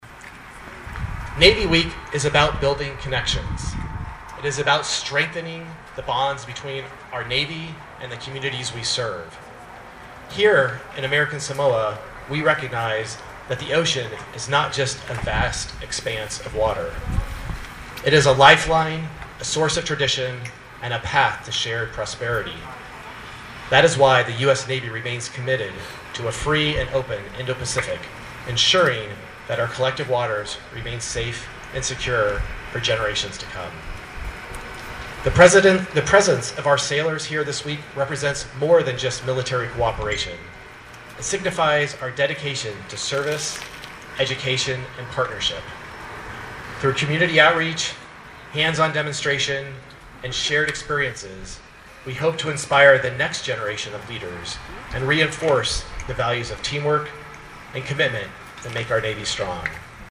The first celebration of U.S. Navy Week in American Samoa kicked off this afternoon with a ceremony at the Veterans Monument.
Rear Admiral Meyer highlighted the contributions of American Samoans to the U.S. Armed Forces. He emphasized that, for generations, the sons and daughters of these islands have served with courage and pride at a rate higher than anywhere else in the country.